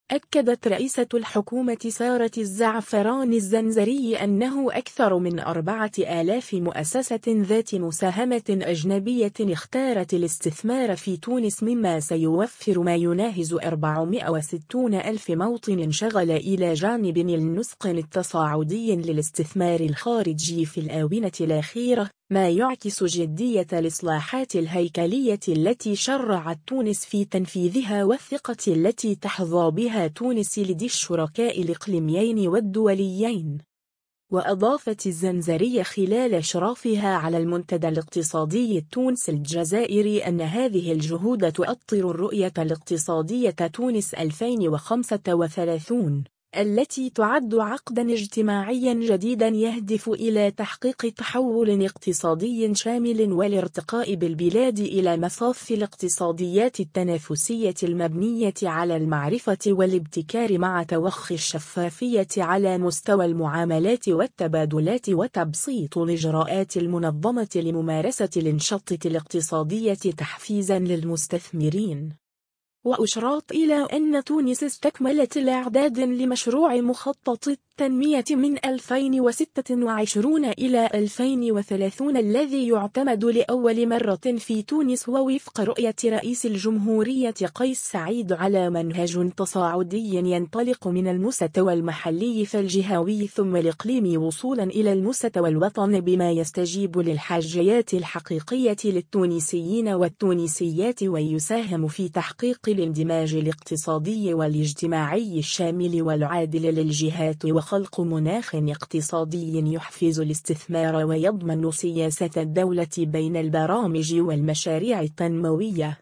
و أضافت الزّنزري خلال اشرافها على المنتدى الاقتصادي التونسي الجزائري أنّ هذه الجهود تؤطّر الرؤية الاقتصادية تونس 2035، التي تعدّ عقدا اجتماعيا جديدا يهدف إلى تحقيق تحول اقتصادي شامل و الارتقاء بالبلاد إلى مصاف الاقتصاديات التنافسية المبنية على المعرفة و الابتكار مع توخي الشفافية على مستوى المعاملات و التبادلات و تبسيط الاجراءات المنظّمة لممارسة الانشطة الاقتصادية تحفيزا للمستثمرين.